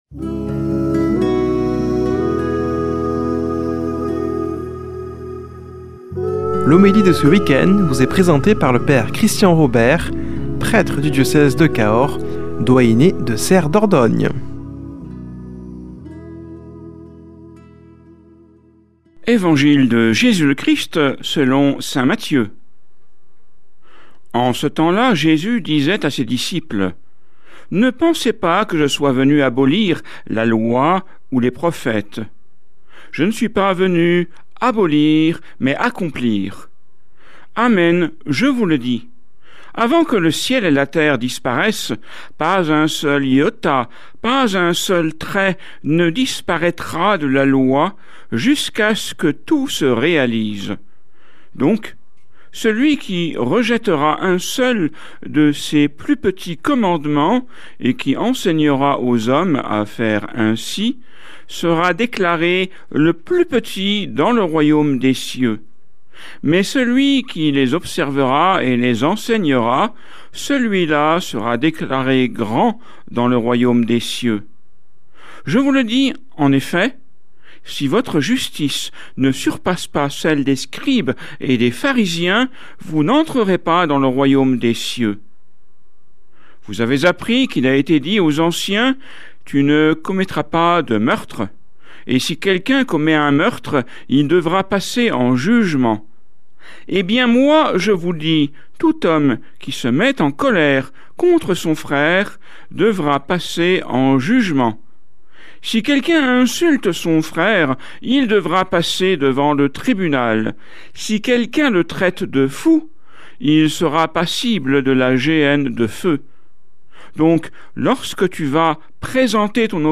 Homélie du 14 févr.